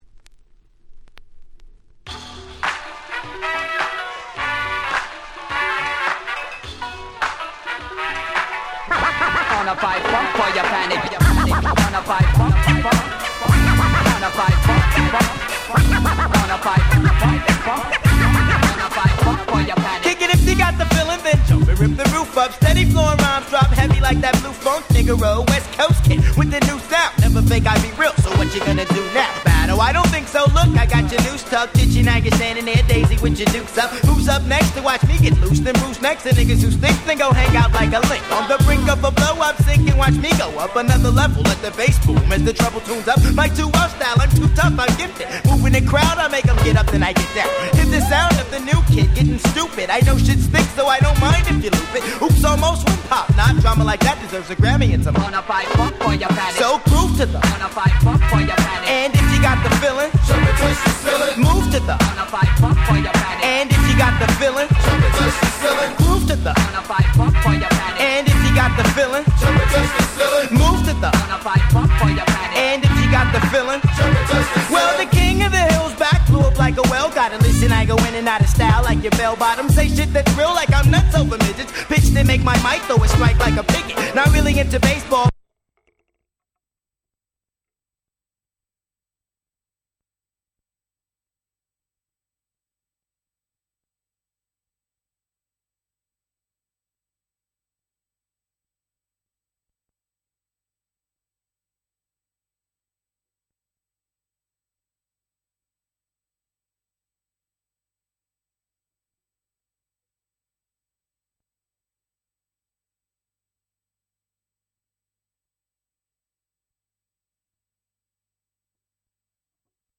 94' Nice West Coast Hip Hop !!
両面共にキャッチーでFunkyなウエッサイナンバー！！